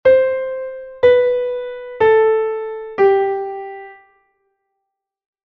do-si-la-sol